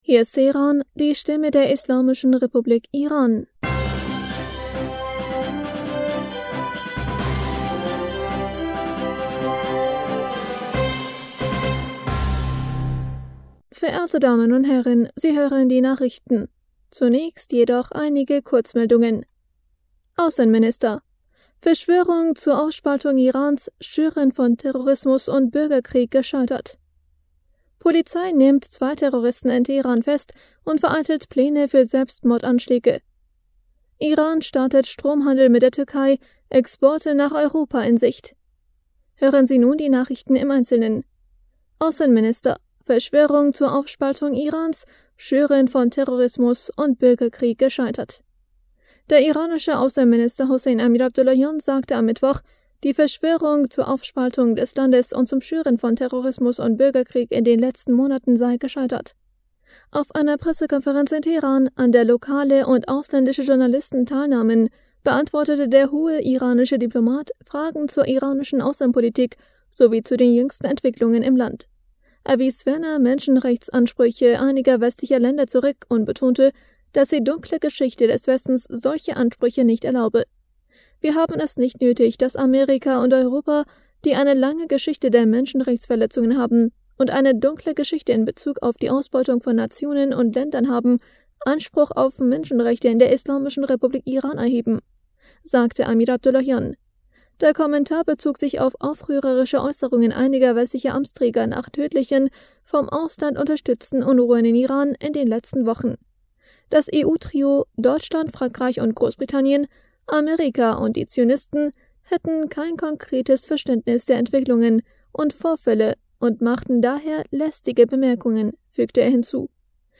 Nachrichten vom 24. November 2022